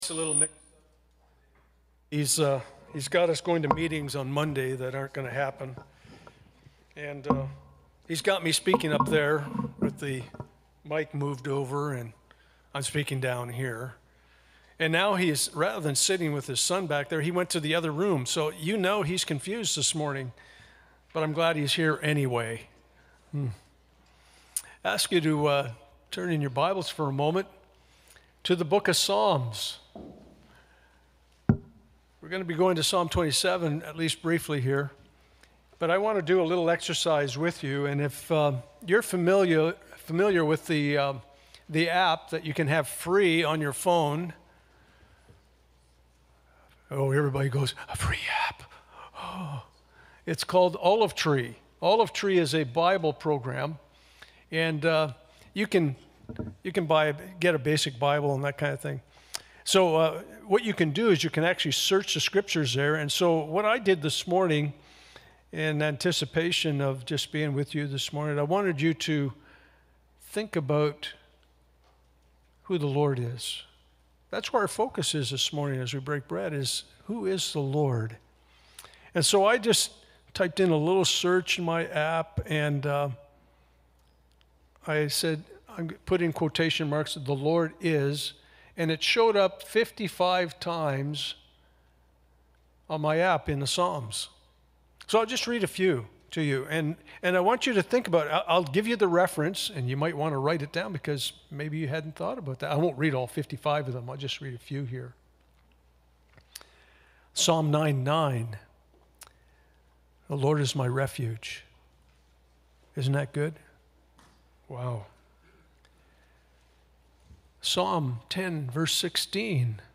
Communion Passage: 2 Peter 3:1-9 Service Type: Sunday Morning « Our God Is Impartial